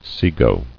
[se·go]